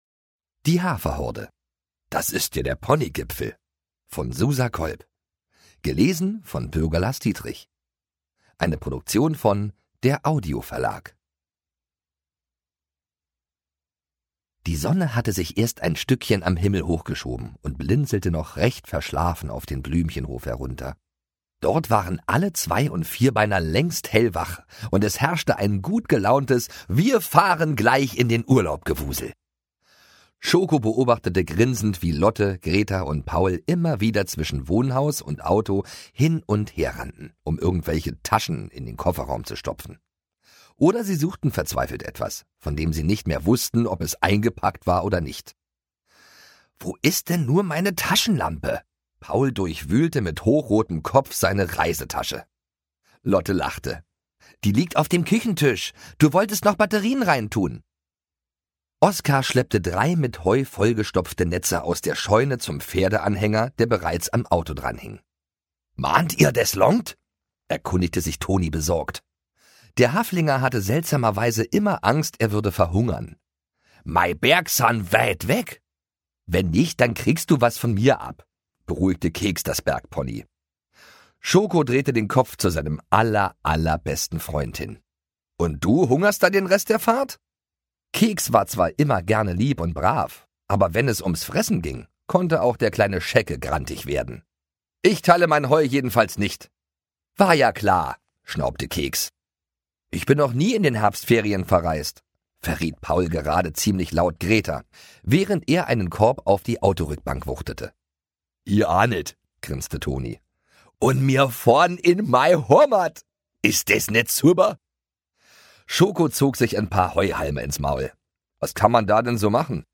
Ungekürzte Lesungen mit Bürger Lars Dietrich (6 CDs)
Bürger Lars Dietrich (Sprecher)